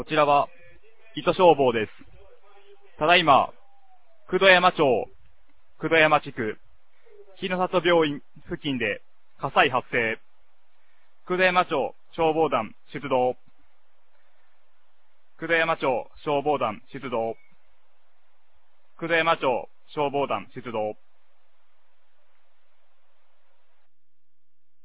2025年06月05日 09時11分に、九度山町より全地区へ放送がありました。